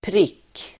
Uttal: [prik:]